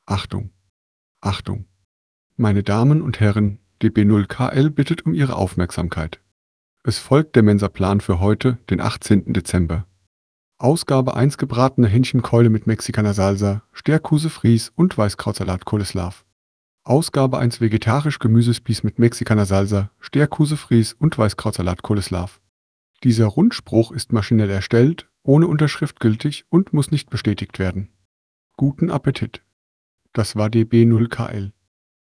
mensa-tts - Daily mensa broadcasts from DB0KL